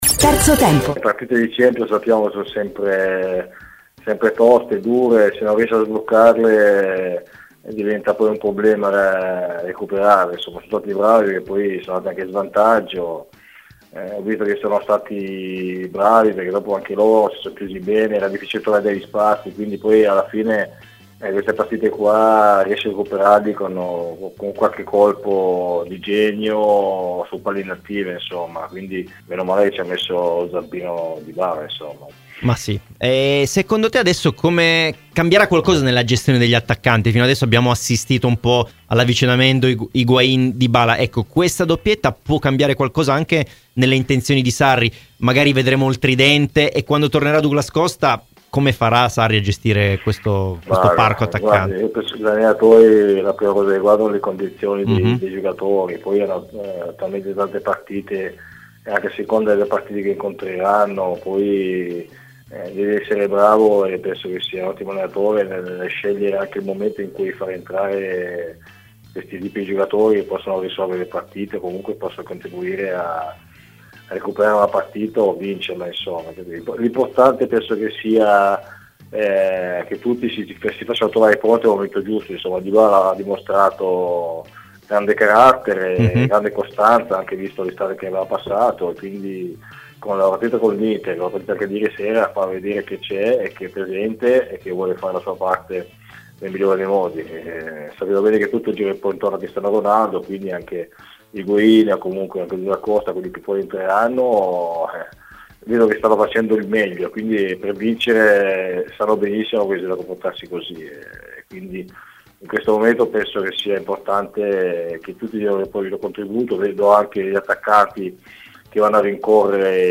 Ai microfoni di Radio Bianconera, nel corso di ‘Terzo Tempo’, è intervenuto l’ex bianconero Roberto Galia: “Le partite di Champions sono sempre toste, dure, poi è un problema recuperarle dopo lo svantaggio. La Juve è stata brava perché loro si sono chiusi bene, serve sempre un colpo di genio per recuperare questo tipo di partite”.
Roberto Galia a "Terzo tempo". © registrazione di Radio Bianconera